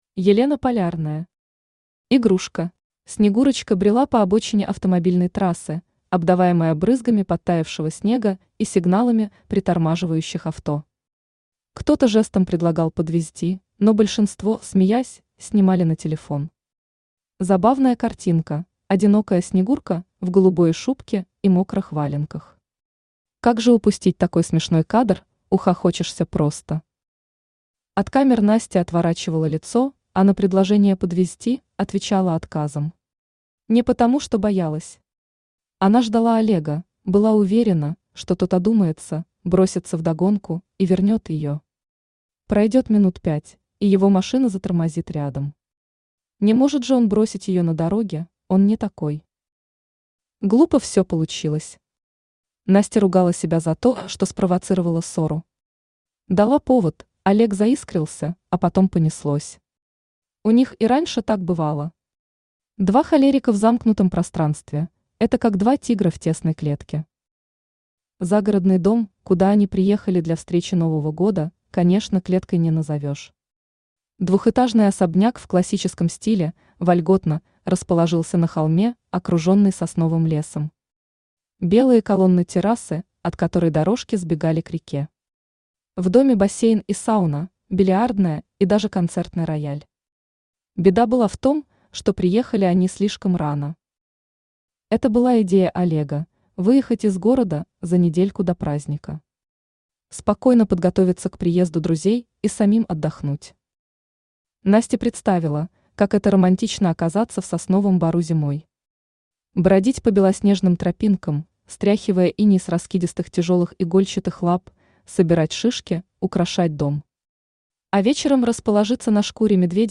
Аудиокнига Игрушка | Библиотека аудиокниг
Aудиокнига Игрушка Автор Елена Андреевна Полярная Читает аудиокнигу Авточтец ЛитРес.